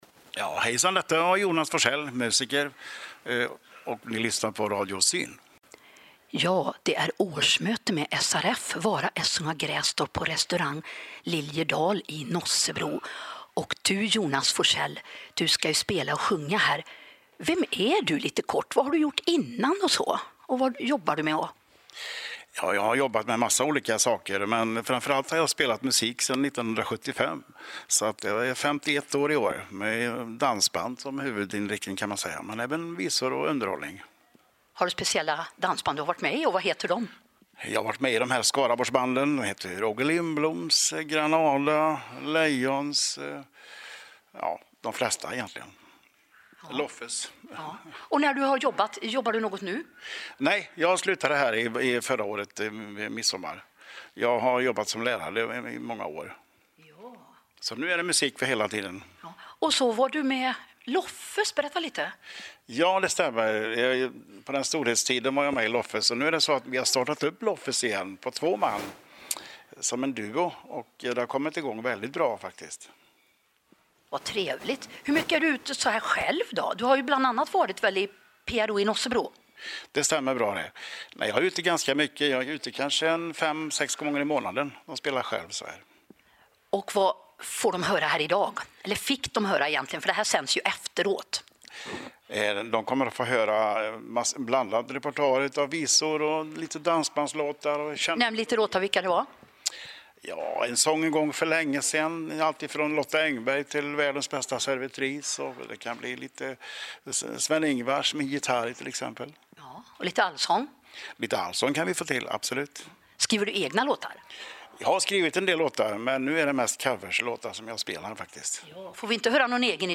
På torsdagen samlades man till årsmöte.
Fina bilder och trevlig intervju.